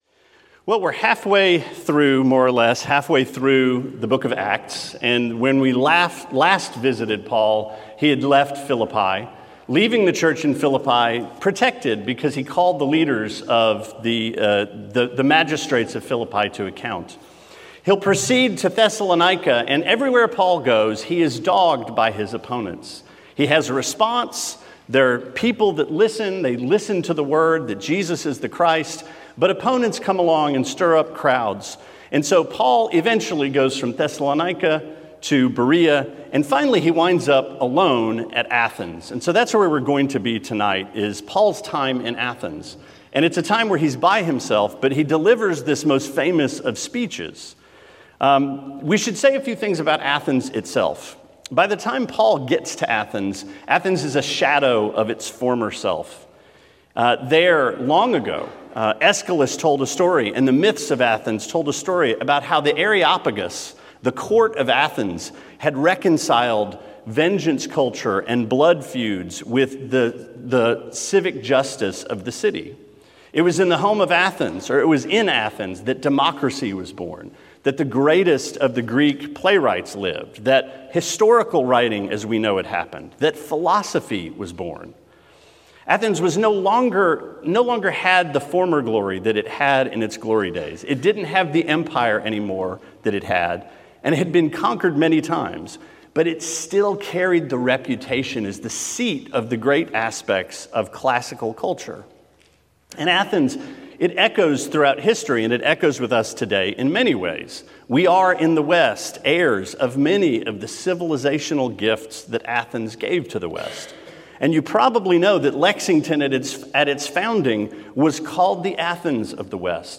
Sermon 2/27: Acts 17: To The Unknown Made Known